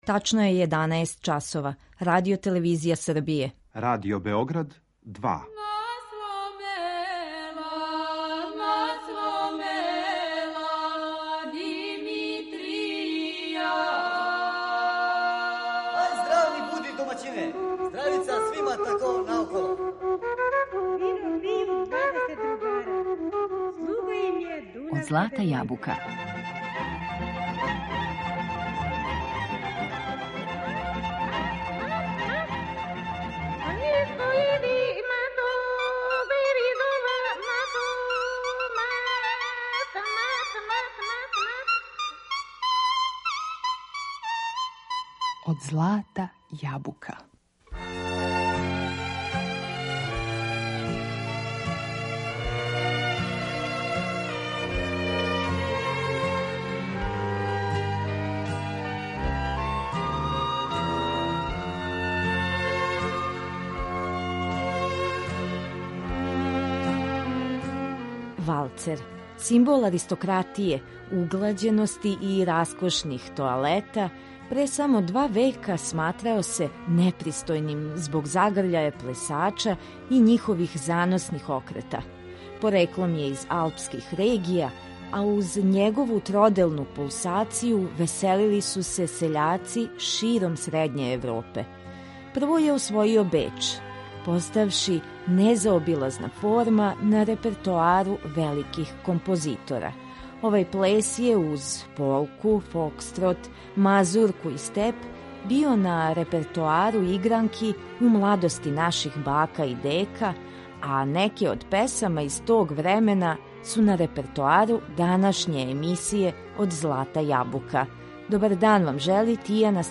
Валцер
Овај плес је, уз полку, фокстрот, мазурку и степ, био на репертоару игранки у младости наших бака и дека, а неке од песама из тог времена су на репертоару данашње емисије Од злата јабука.